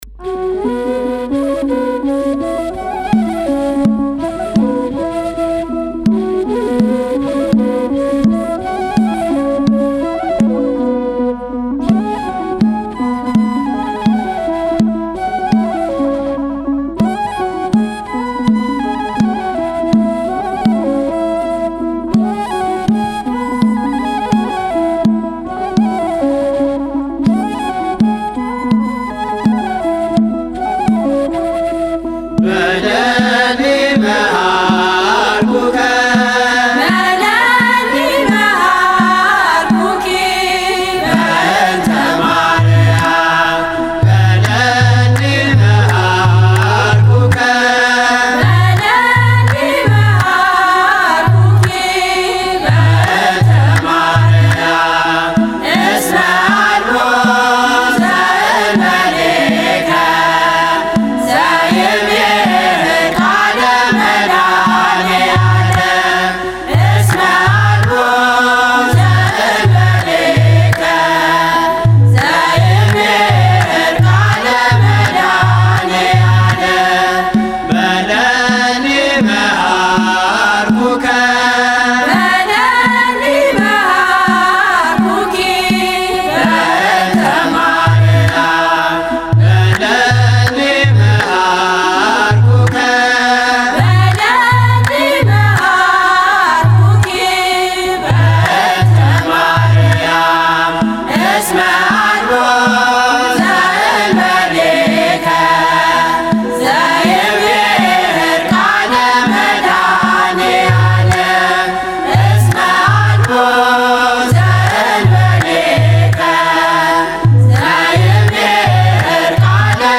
መዝሙር (በለኒ መሐርኩከ) July 1, 2018
Re'ese Adbarat Tserha Aryam Kidist Selassie Cathedral Ethiopian Orthodox Tewahedo Church --- Minneapolis, Minnesota